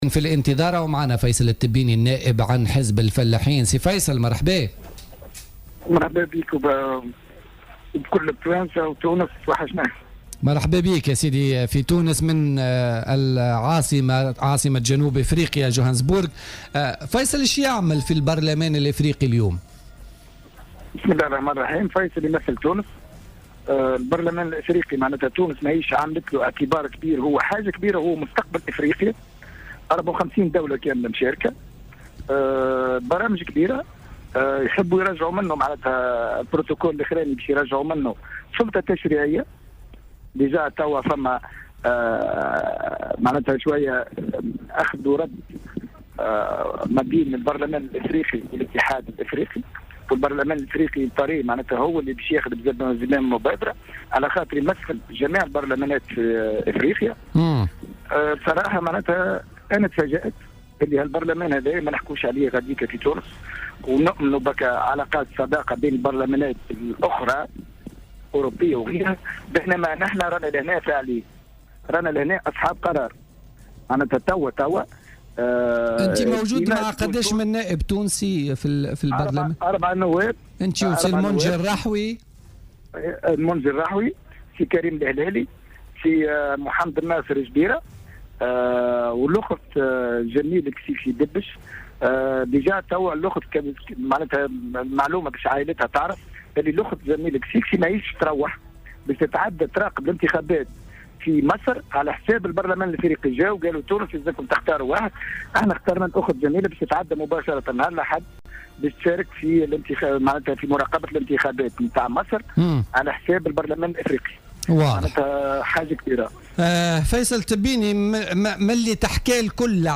وقال التبيني في مداخلة هاتفية من جوهانسبرغ لبرنامج "بوليتيكا" بـ"الجوهرة أف أم"، إن جدلا قائما بأوساط المنظمات الإفريقية حول الدور الذي يلعبه الاتحاد والبرلمان الإفريقيين بالقارة الإفريقية وأهميتهما على مستوى العلاقات الدولية.